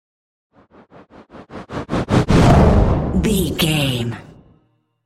Dramatic whoosh to hit trailer
Sound Effects
Atonal
dark
intense
tension